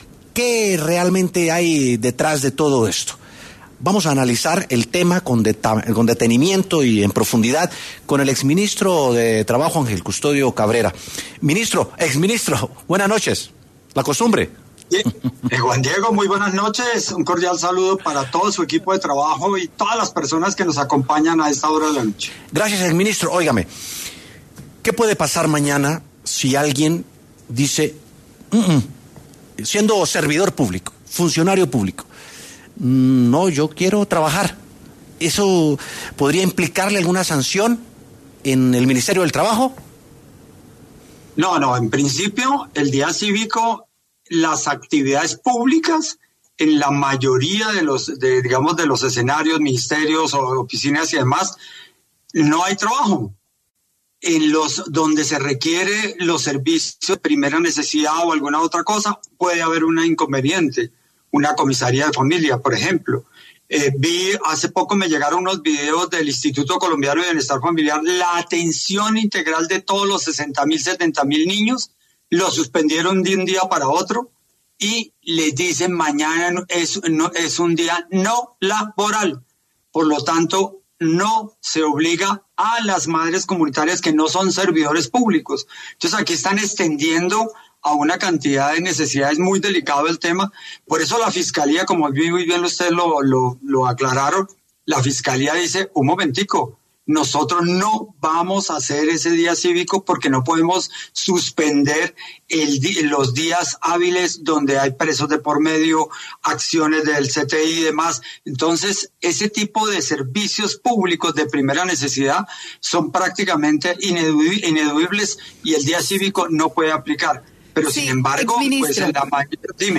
El exministro de Trabajo Ángel Custodio Cabrera pasó por los micrófonos de W Sin Carreta para hablar sobre el impacto que tendrá el día cívico decretado por el Gobierno Nacional para este martes 18 de marzo.